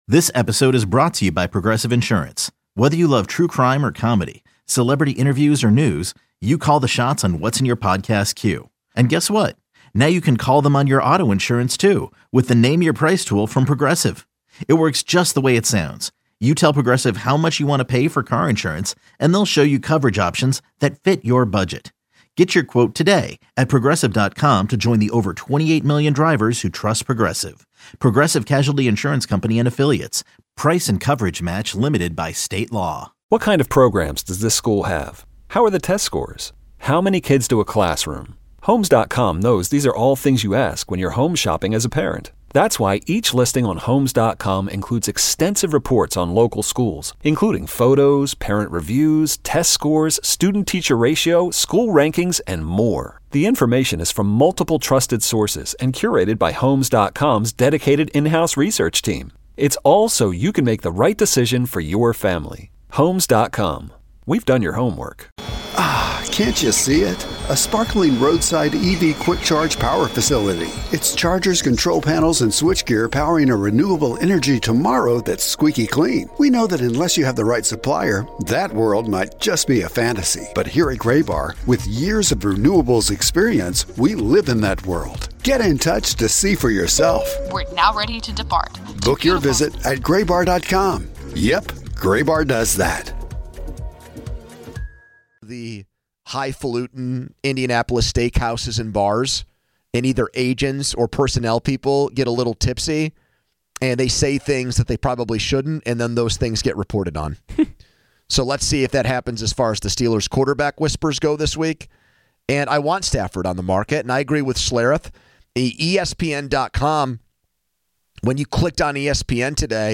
Is a 1st round pick too rich at this point for Stafford? Open Phone Friday.